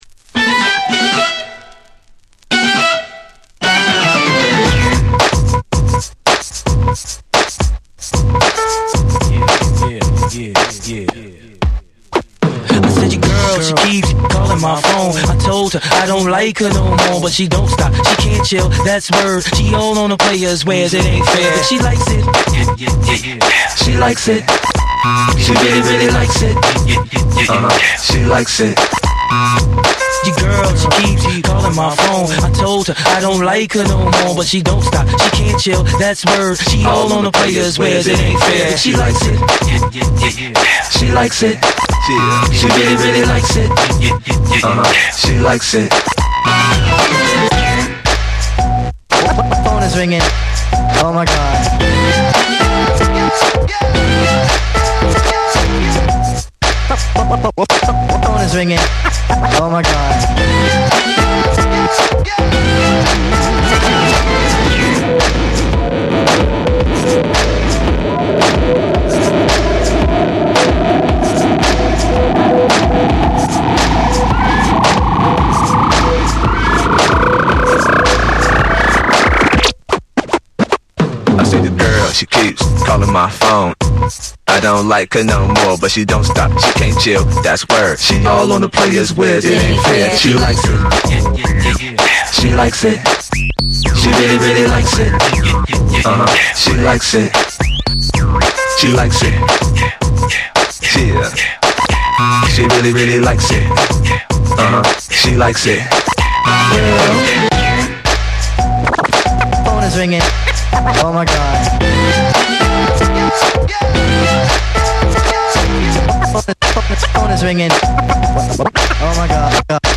グリッチ感のあるビートメイクとジャジーで遊び心にあふれたメロディが絡み合う、独特の世界観が光る作品。
BREAKBEATS